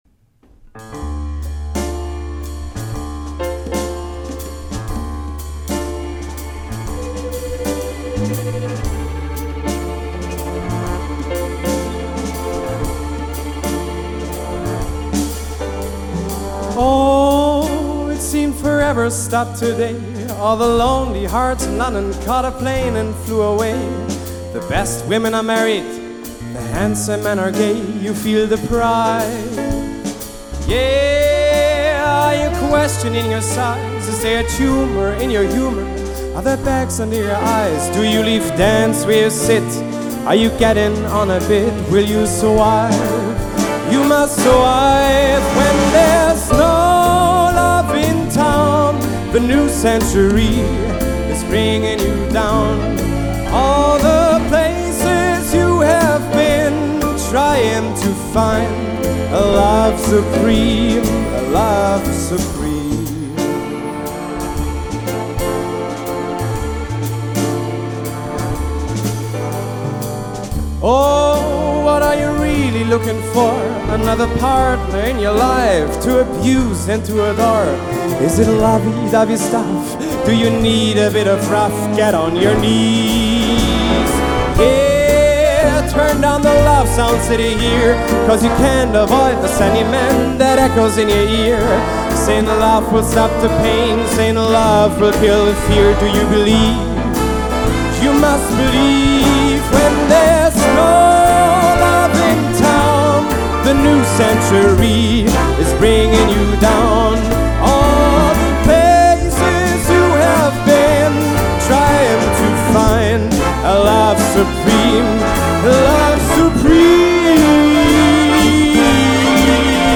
25 Musiker – ein Sound.
Jazz, Soul oder Pop: Die Bandbreite ist groß.